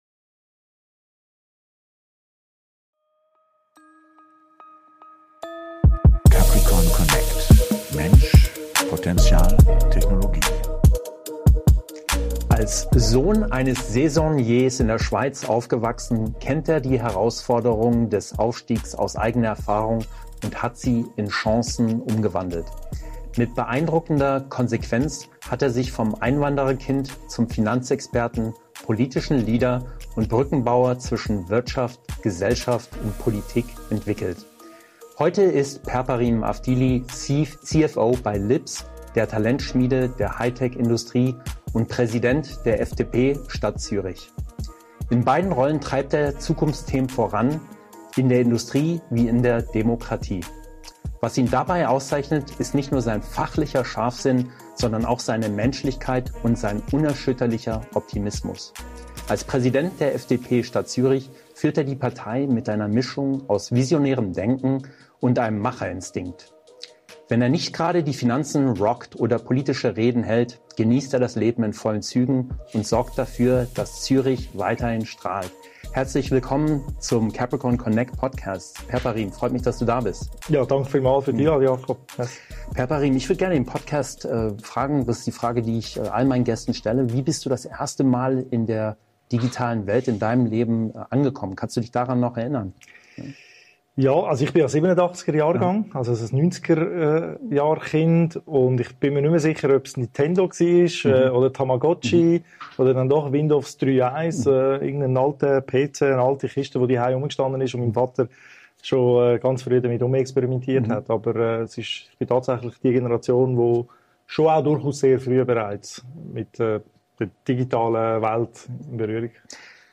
#42 - Interview mit Përparim Avdili - Präsident FDP Stadt Zürich - Gemeinderat - CFO ~ CapricornConnect: People, Potential, Technology.